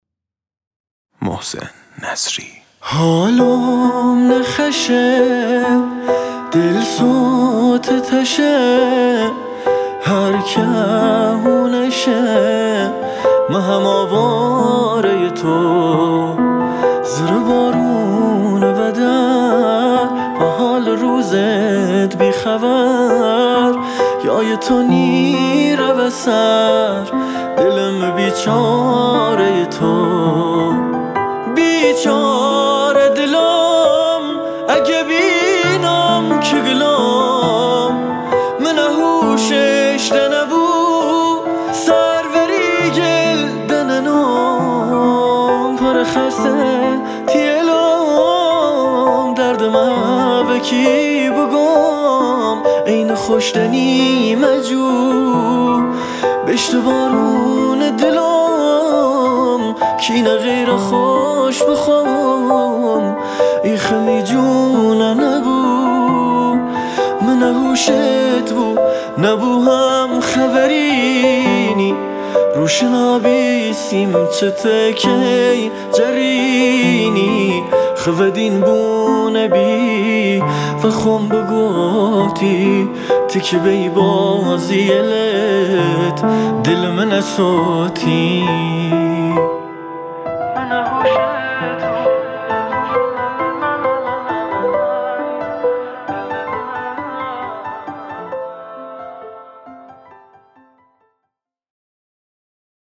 آهنگ لری جدید